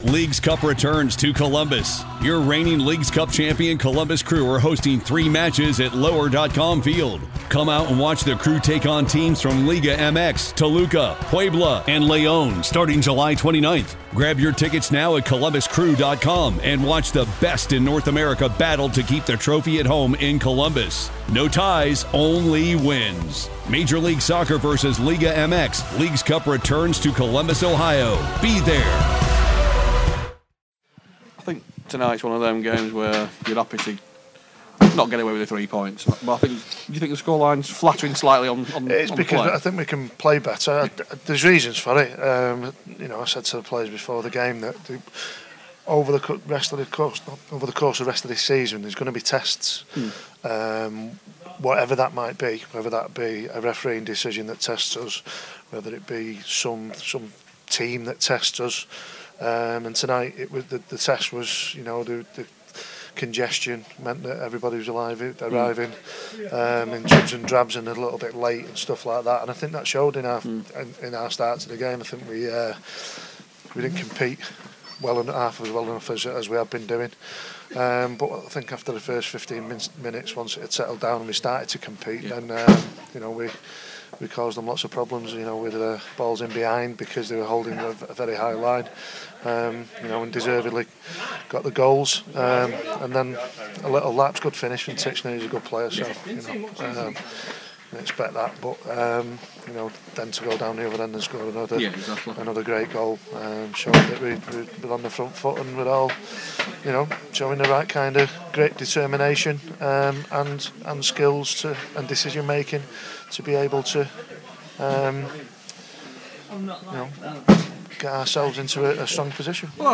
Post Match Interview